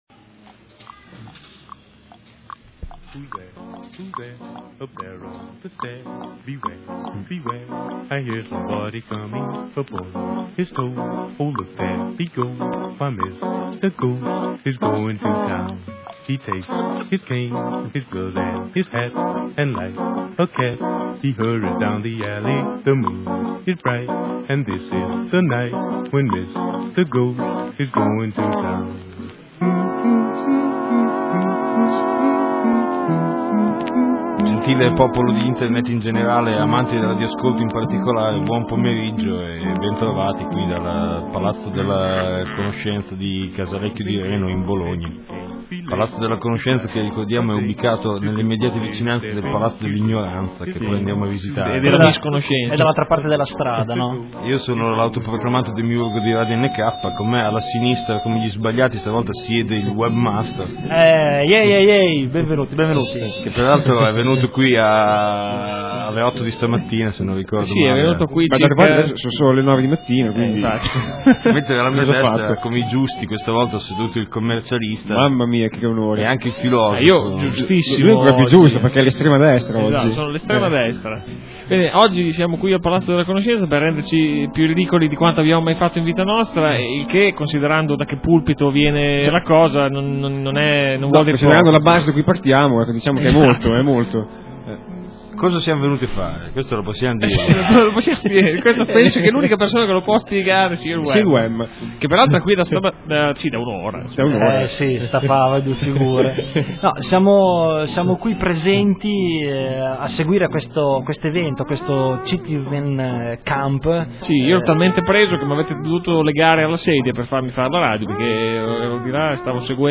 COA #66 – SPECIALE NK LIVE – CitizenCamp alla Casa della Canoscenza. | Radio NK